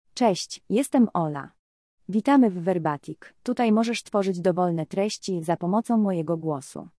Ola — Female Polish AI voice
Ola is a female AI voice for Polish.
Voice sample
Listen to Ola's female Polish voice.
Female
Ola delivers clear pronunciation with authentic Polish intonation, making your content sound professionally produced.